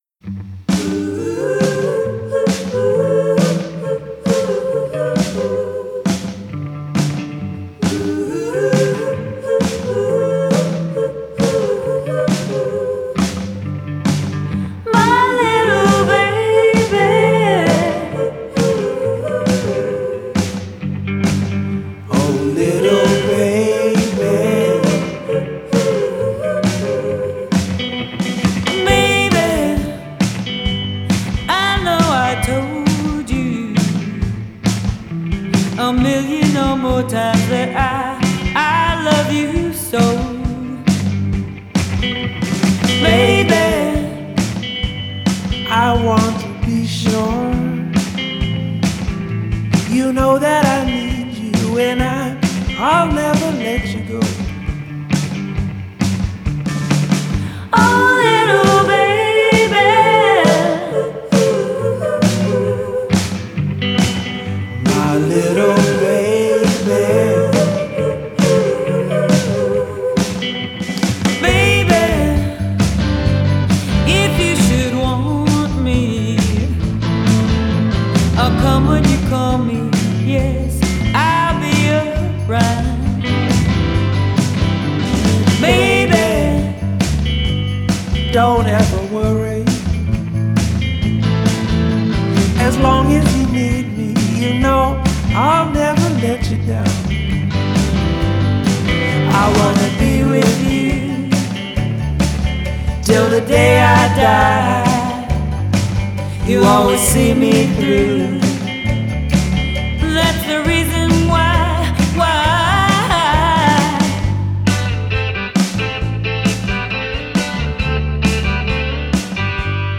Genre: Indie Pop, Twee Pop